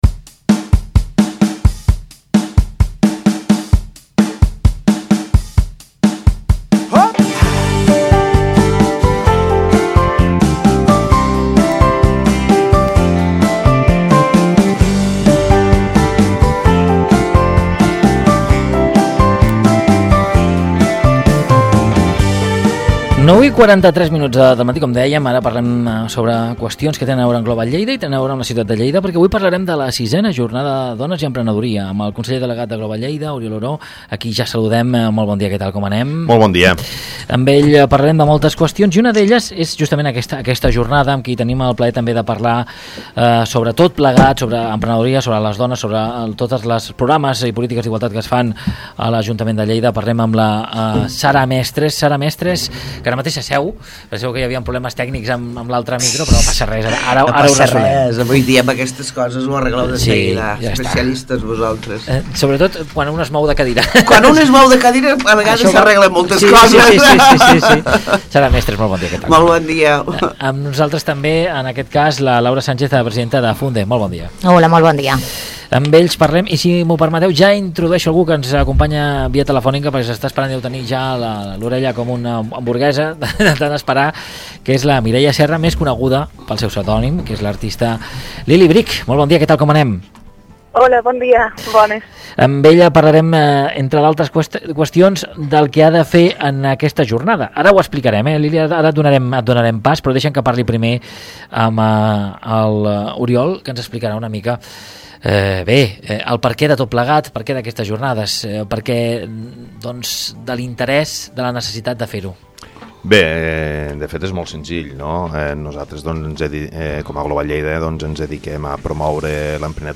Avui a UA1 Ràdio hem parlat de dones i emprenedoria - GLOBALLEIDA